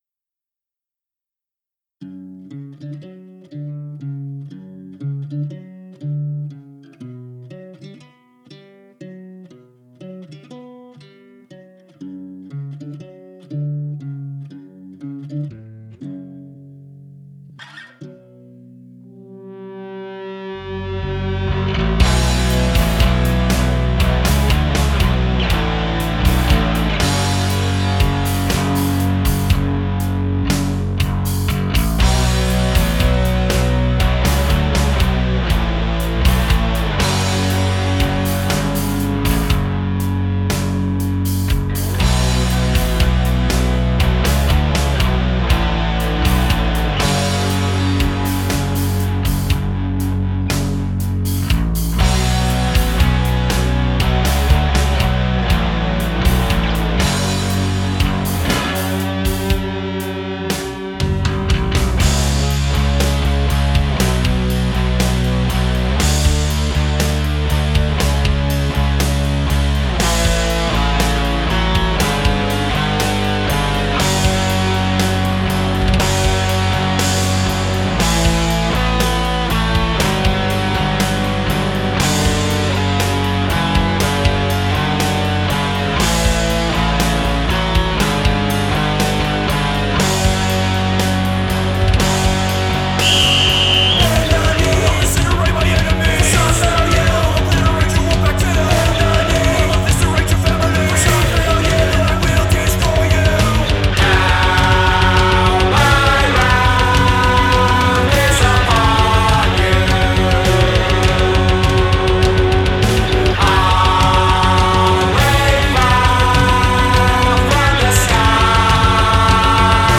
I liked the intro a lot.
I like how you guys sing.
DANGEROUSLY BOMBASTIC